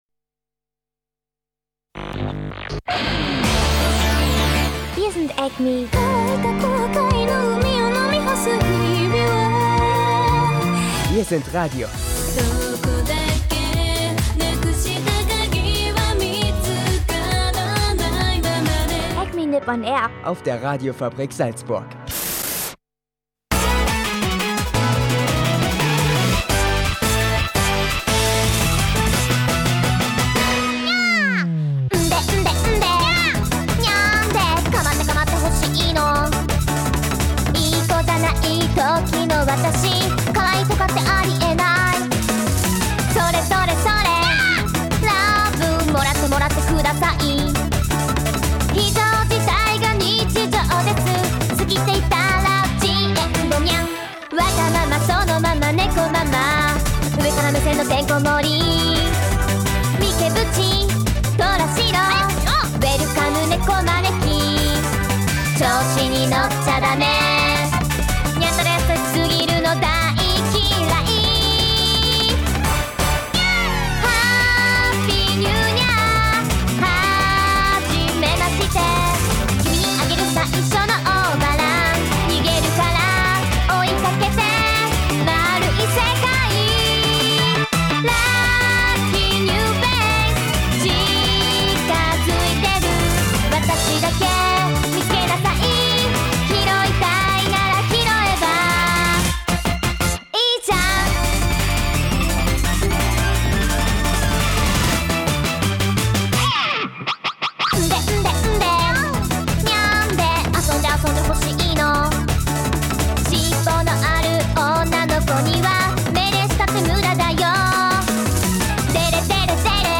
Acme.Nipp-on-Air war bei der Con, die wohl den für die Fanszene passendsten Namen trägt: Die Nyancon in Linz. Hier trafen sich Ende Mai rund 1500 Animefans aus Österreich und Umgebung und genossen ein sehr heißes Wochenende.